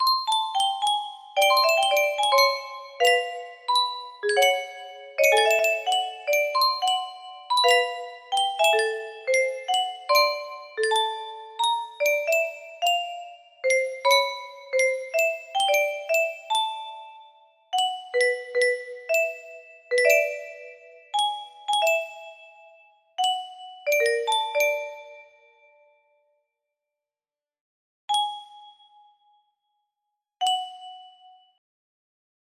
you mom. music box melody
Yay! It looks like this melody can be played offline on a 30 note paper strip music box!